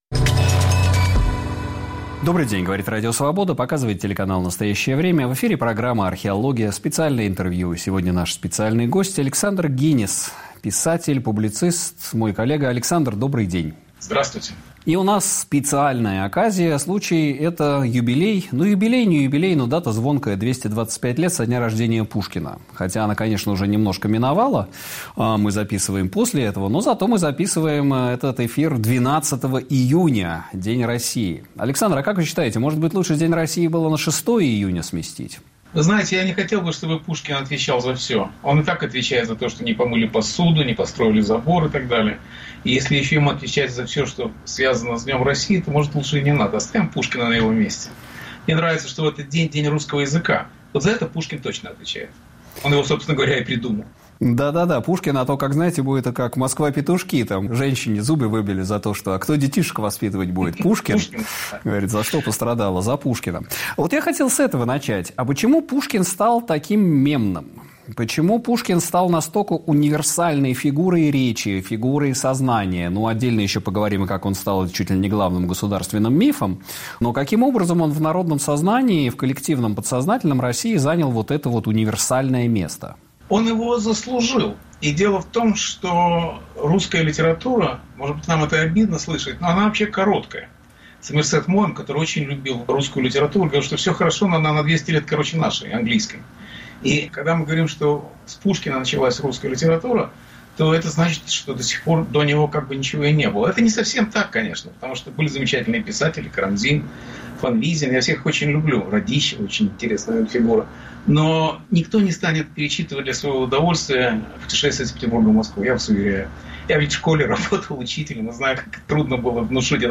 На вопросы Сергея Медведева отвечает писатель и публицист Александр Генис.